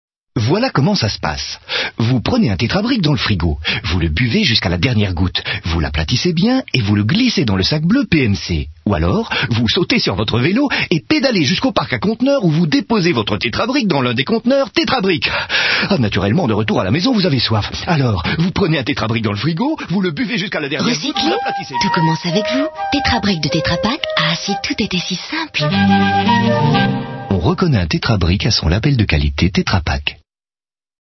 Pubs Radio: